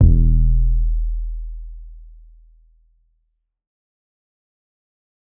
TS 808 4.wav